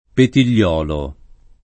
[ petil’l’ 0 lo ]